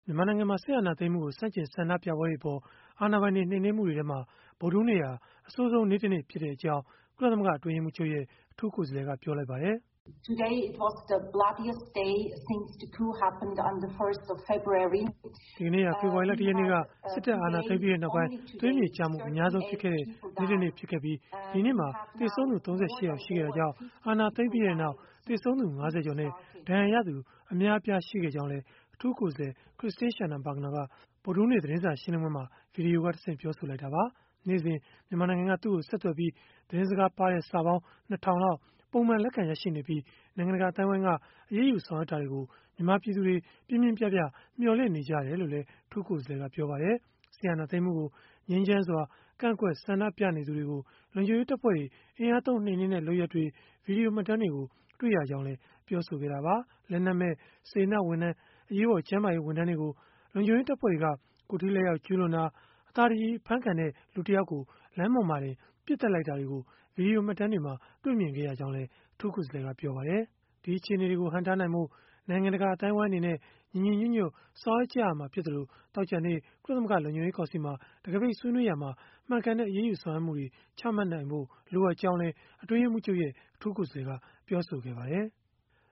"ဒီကနေ့ဟာ ဖေဖော်ဝါရီလ ၁ ရက်နေ့က စစ်တပ်အာဏာသိမ်းပြီးနောက်ပိုင်း သွေးမြေကျမှု အများဆုံးဖြစ်ခဲ့တဲ့ နေ့တနေ့ ဖြစ်ခဲ့ပြီး ဒီနေ့မှာ သေဆုံးသူ ၃၈ ယောက် ရှိခဲ့တာကြောင့် အာဏာသိမ်းပြီးနောက် သေဆုံးသူ ၅၀ ကျော်နဲ့ ဒဏ်ရာရသူ အများအပြား ရှိခဲ့ကြောင်းလည်း" အထူးကိုယ်စားလှယ် Christine Schraner Burgener က ဗုဒ္ဓဟူးနေ့ သတင်းစာရှင်းလင်းပွဲမှာ ဗွီဒီယိုကတဆင့် ပြောဆိုခဲ့တာပါ။